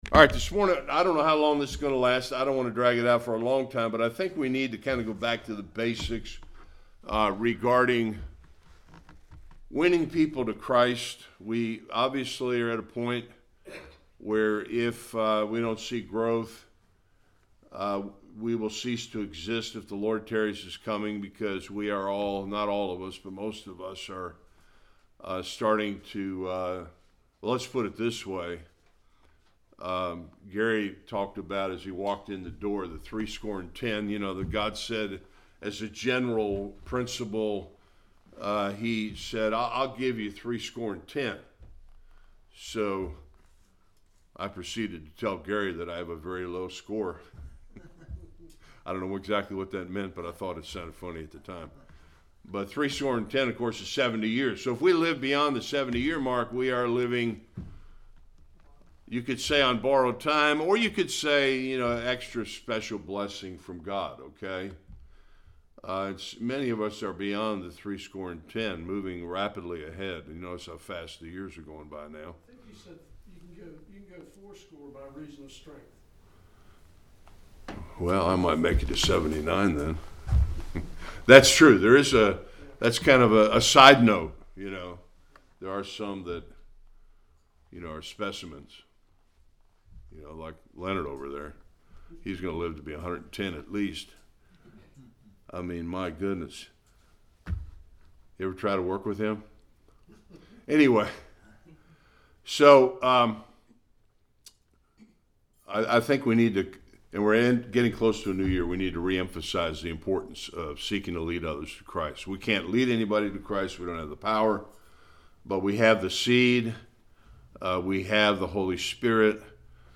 Various Passages Service Type: Sunday School We have been given a mandate by our Lord to seek to win others to Him.